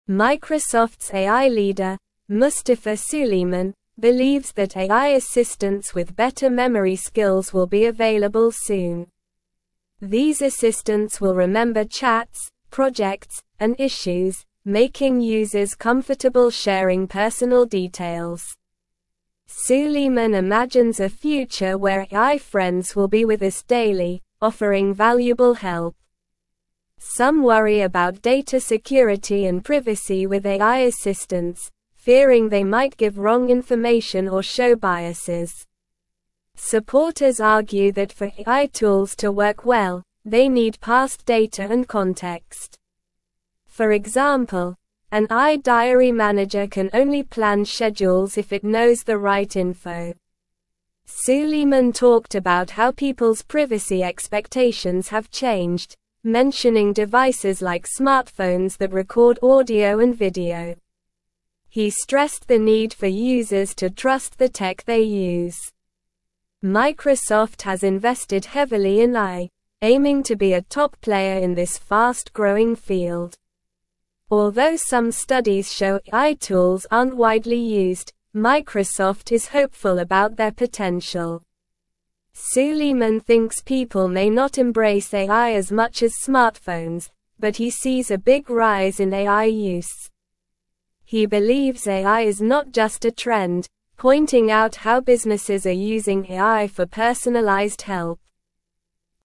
Slow
English-Newsroom-Upper-Intermediate-SLOW-Reading-Microsofts-Head-of-AI-Predicts-Advanced-Memory-Assistants.mp3